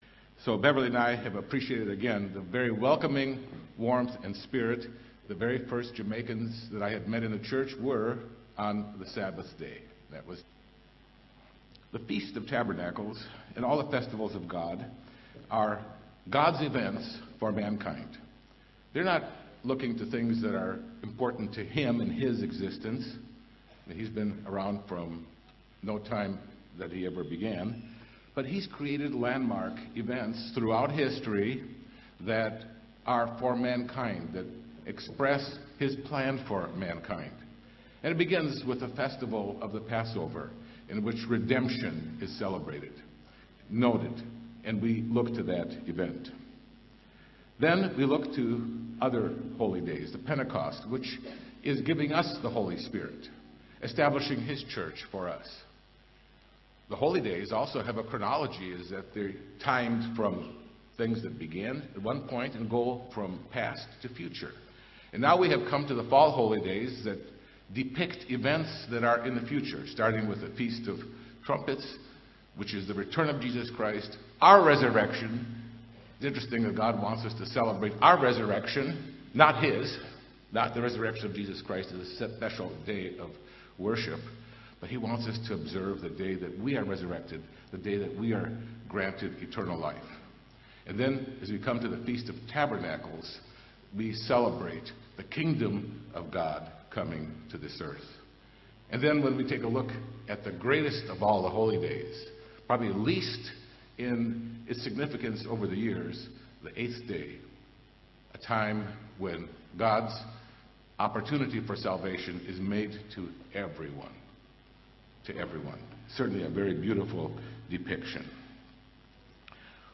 This sermon was given at the Montego Bay, Jamaica 2019 Feast site.